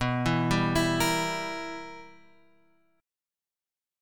BM7sus4#5 chord {7 10 8 9 8 x} chord